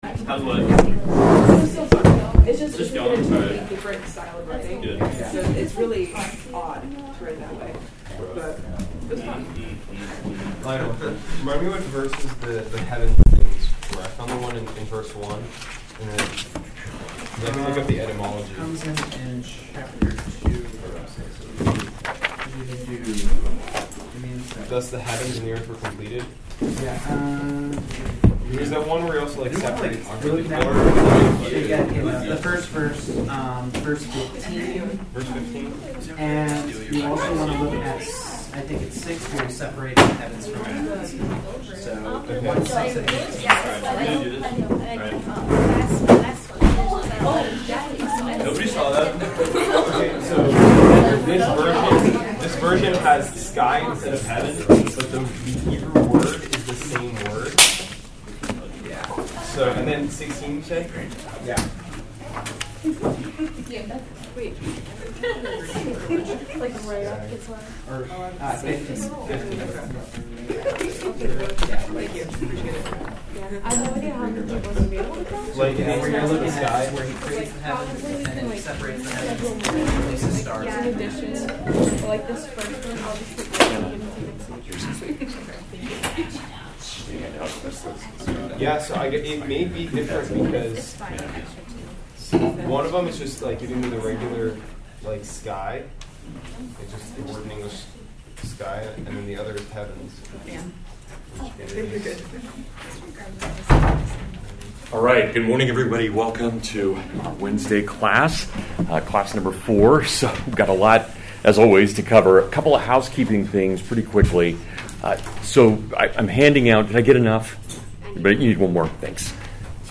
11 Hebrew Themes and Begin Greeks (Full Lecture)